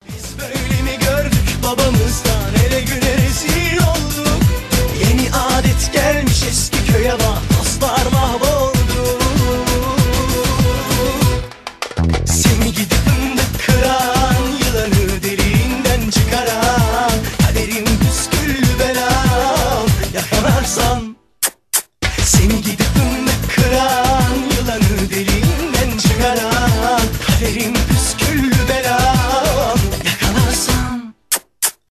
Танцевальные рингтоны
Турецкие рингтоны , поп
зажигательные